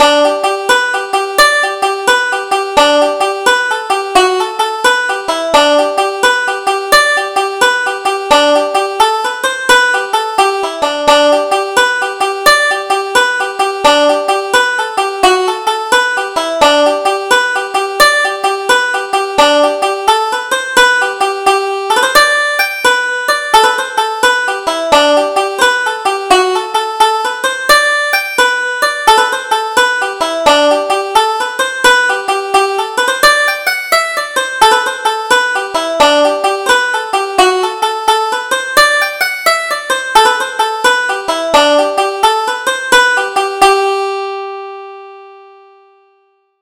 Double Jig: The Woodcock